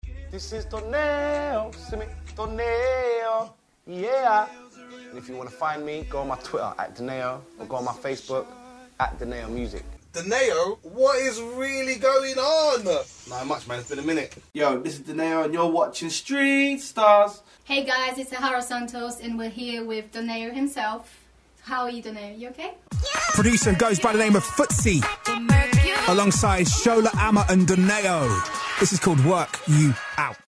ドネーオ　（※もしくはドネオ）
本人の自己紹介・インタビュー、BBC Radio 1